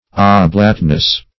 Oblateness \Ob*late"ness\, n.